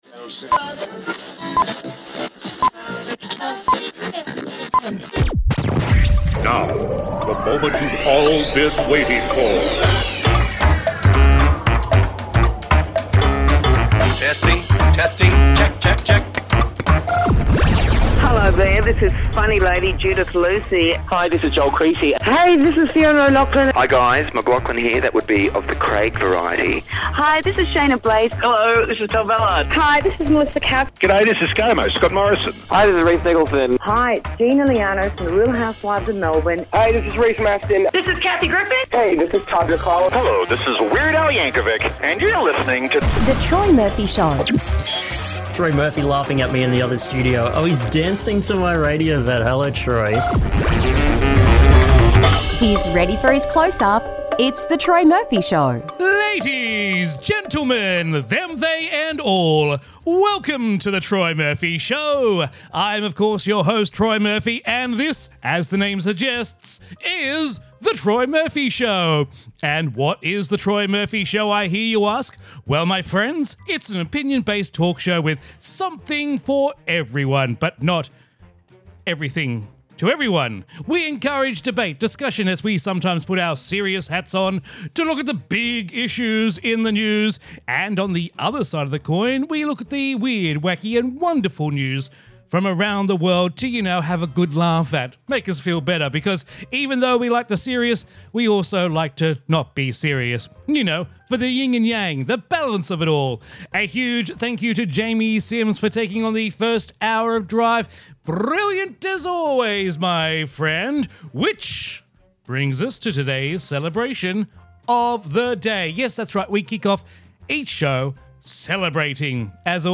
House Band sing Christmas songs
local radio